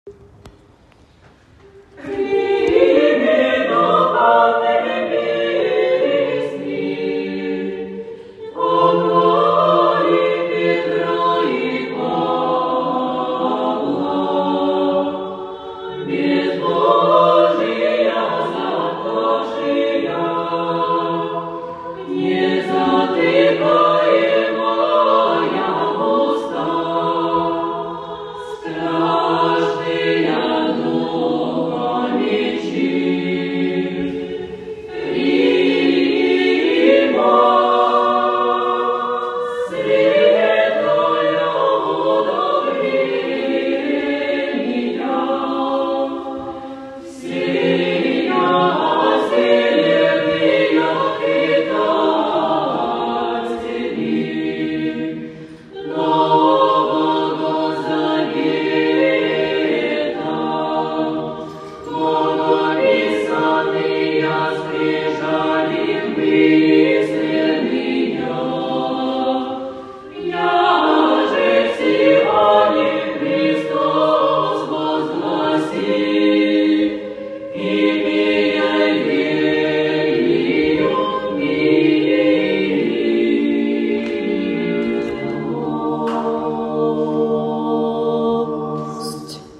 Накануне великого праздника Первоверховных апостолов Петра и Павла на подворье нашей обители состоялось всенощное бдение | Богородице-Рождественский ставропигиальный женский монастырь
Июл 12, 2023 | Аудиофайлы, Новости обители |